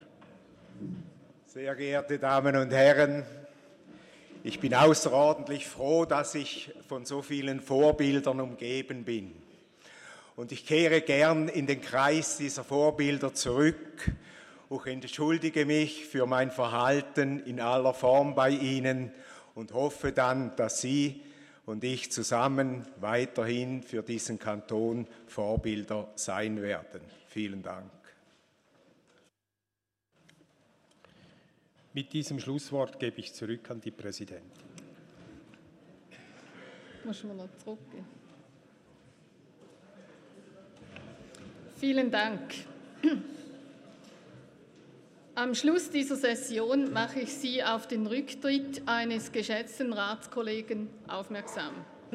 24.4.2019Wortmeldung
Session des Kantonsrates vom 23. und 24. April 2019